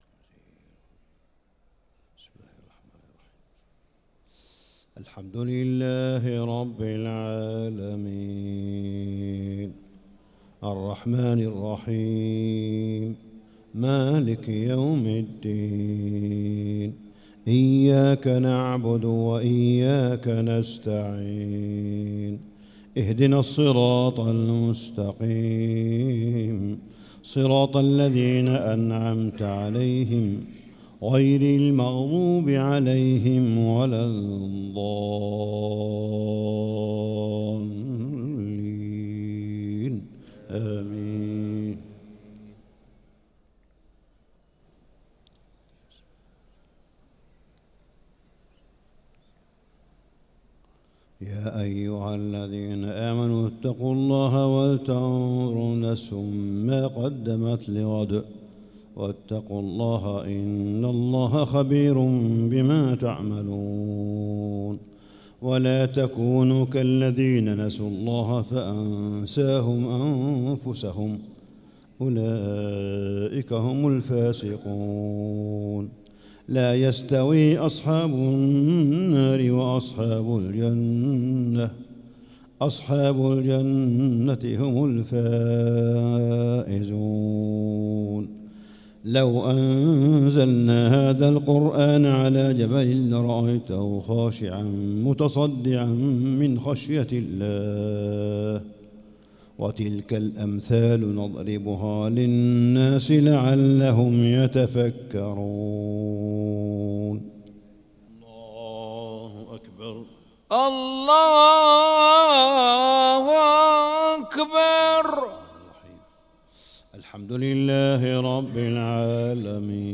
صلاة العشاء للقارئ صالح بن حميد 25 رمضان 1445 هـ